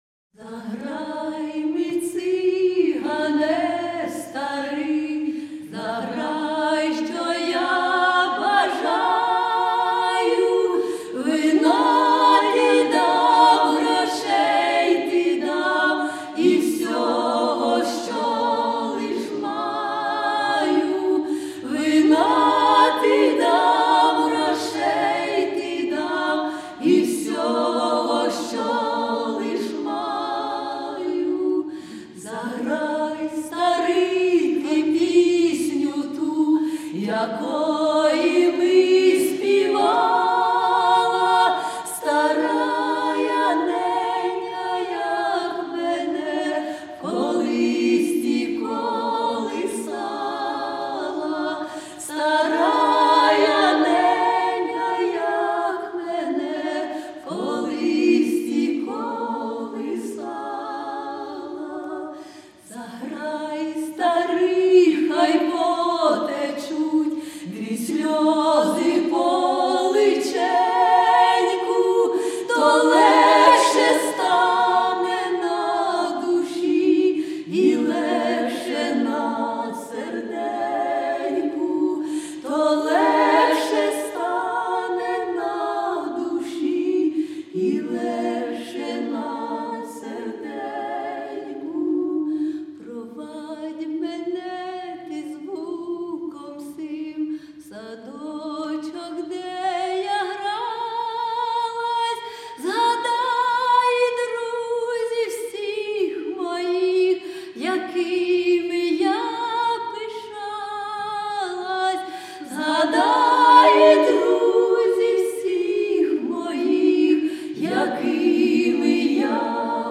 (лірична, романс)